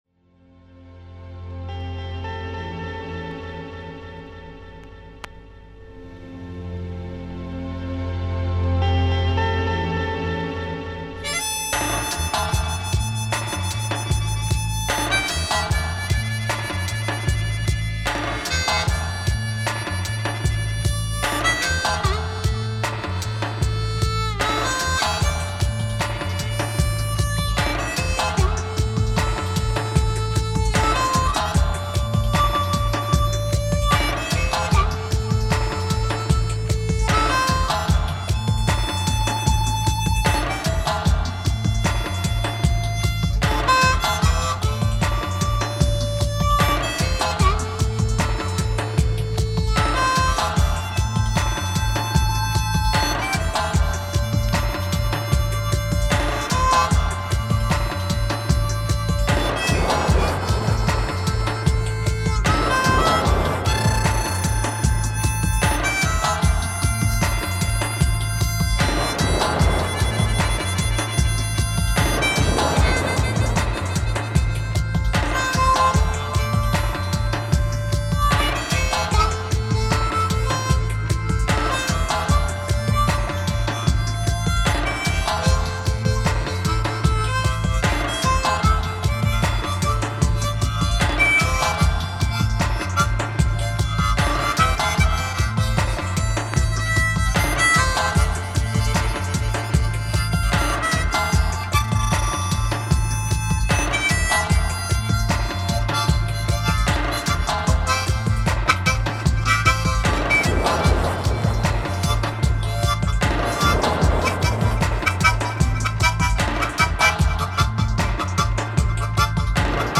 mix